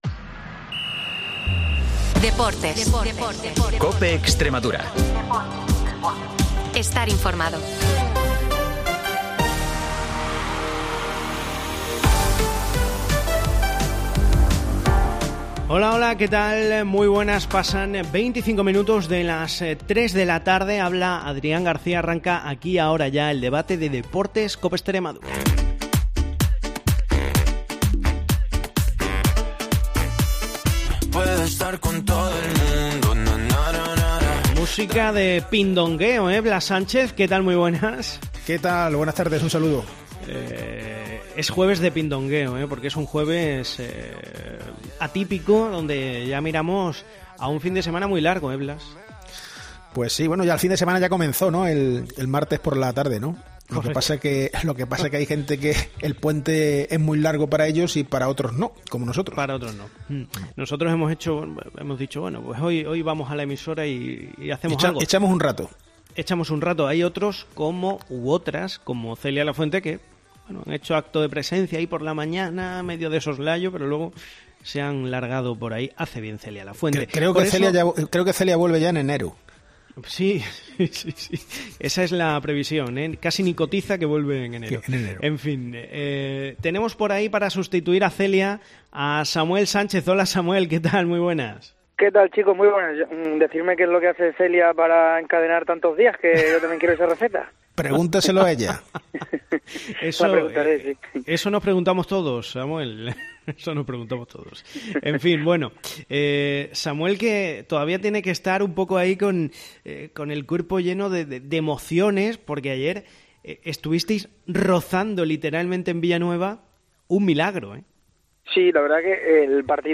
El debate de deportes de COPE Extremadura